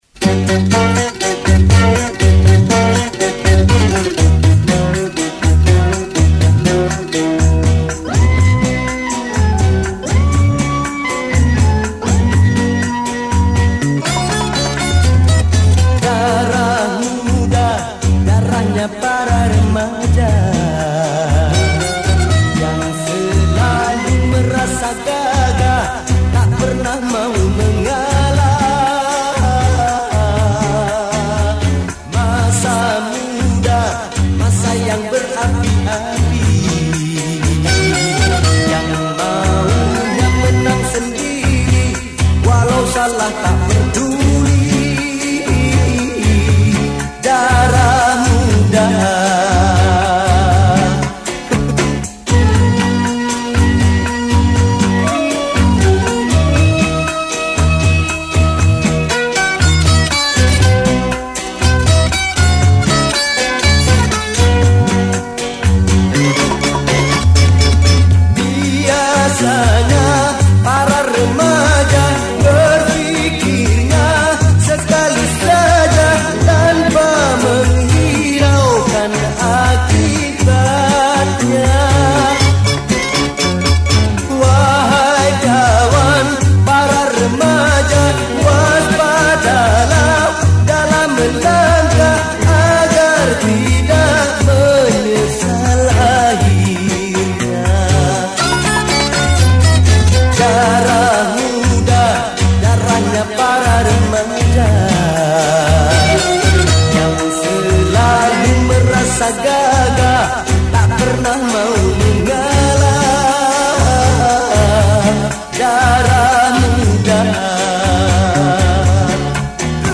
Genre Musik                        : Dangdut